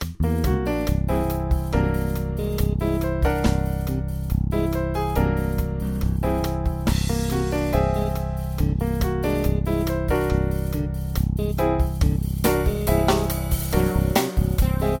Whole Tone Mode